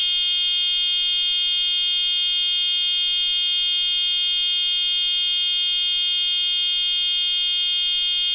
Audio Comparison between an Electro-Mechanical Unit and a Low Frequency Piezo Unit
Both Units @ 400Hz
Electrical-Mechanical Buzzer TRIE-2612P4H
Low-Frequency Piezo TRIP-2612P4H
Low-Frequency-Piezo.mp3